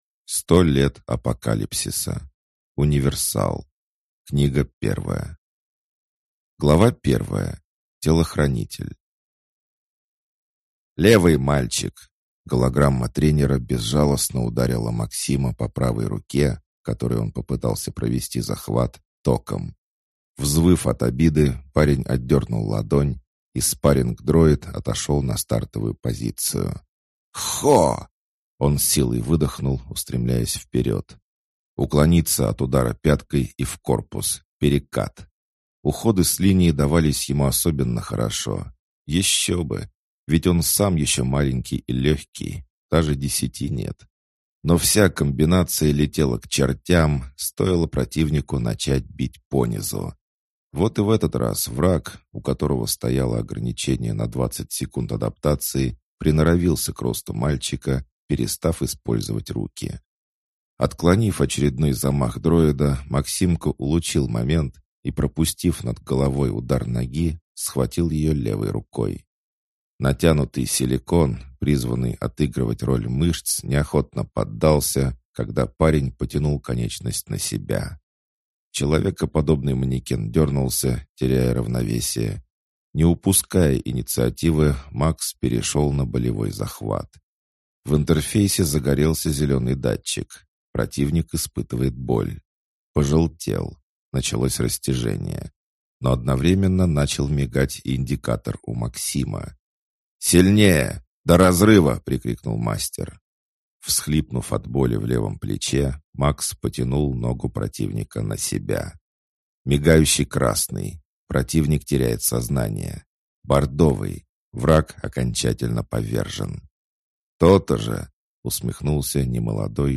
Аудиокнига Универсал. Книга 1 | Библиотека аудиокниг